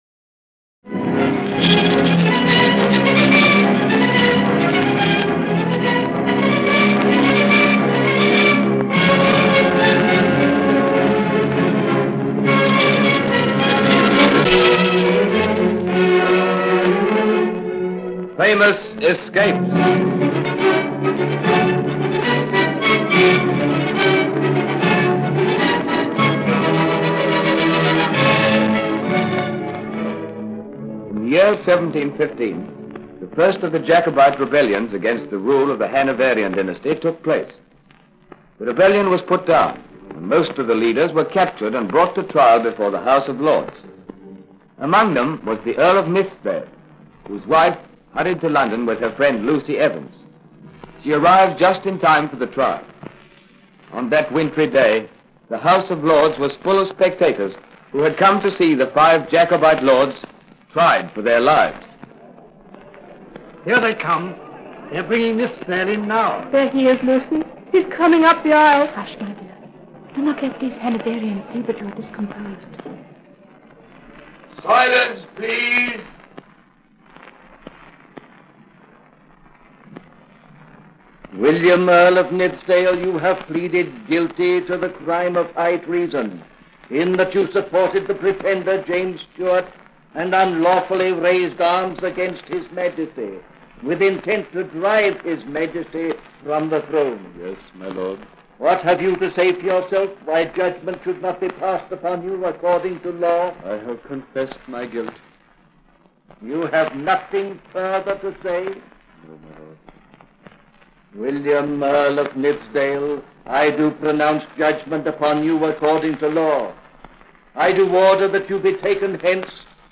Famous Escapes was a captivating radio series produced in Australia around 1945. The show delved into some of the most daring escapes in history, featuring remarkable characters who managed to break free from seemingly impossible situations.